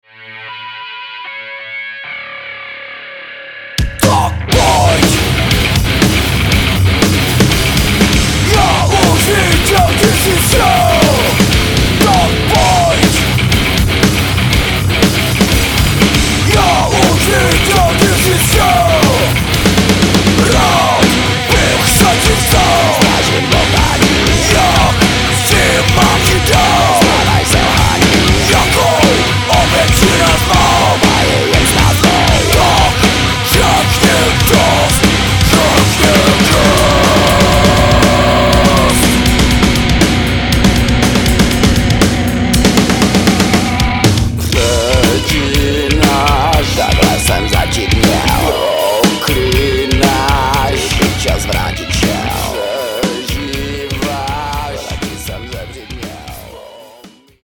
Nahrávací studio v Lipově audio / digital